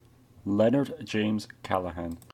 Leonard James Callaghan, Baron Callaghan of Cardiff (/ˈkæləhæn/
En-Leonard-James-Callaghan.oga.mp3